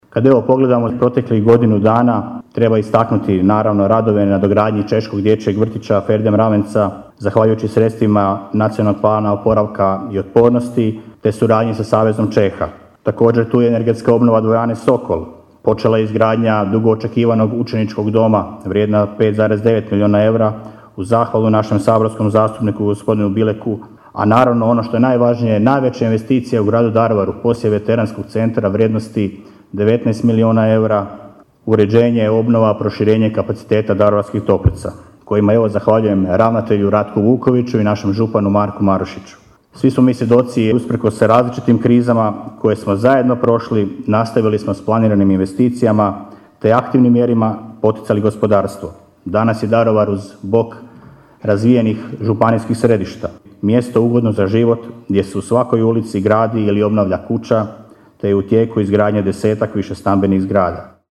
Svečana sjednica u povodu Dana Grada Daruvara održana je u dvorani Gradskog kina Pučkog otvorenog učilišta.
Retrospektivu urađenog posla kroz proteklih godinu dana na svečanoj sjednici pružio je gradonačelnik Daruvara Damir Lneniček.